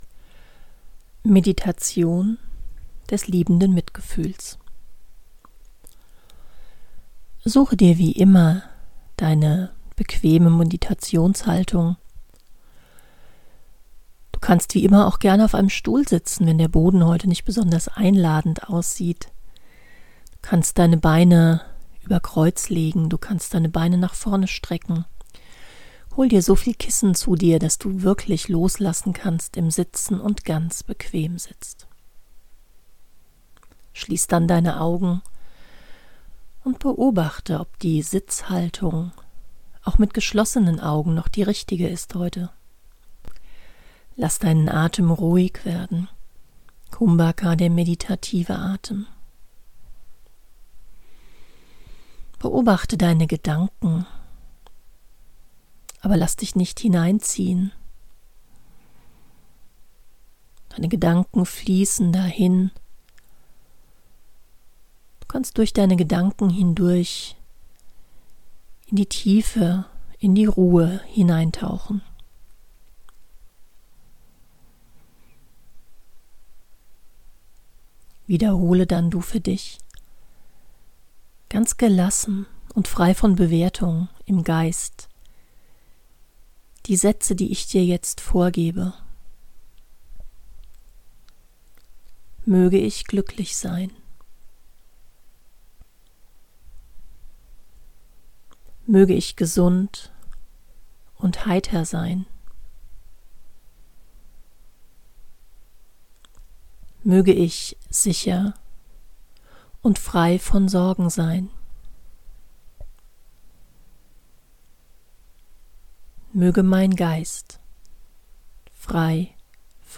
metta-meditation.mp3